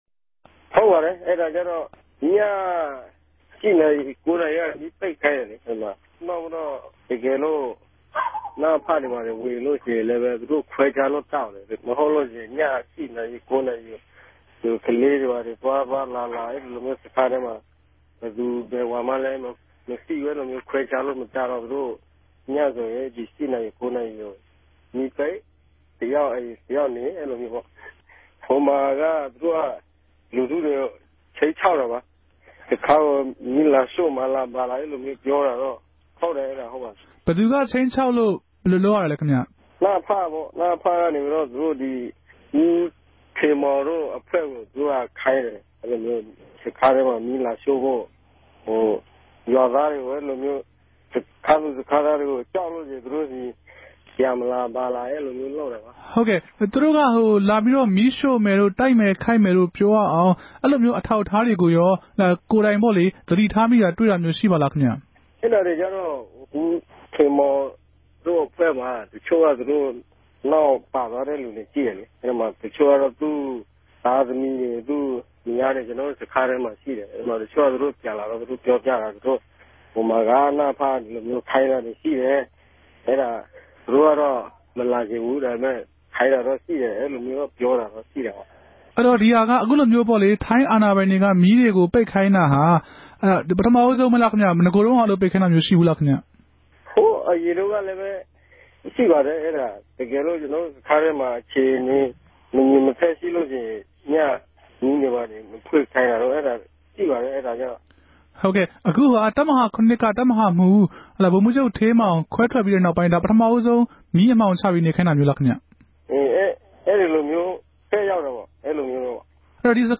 KNU ပဋိပက္ခမဵားအပေၞ သတင်းသုံးသပ်ခဵက်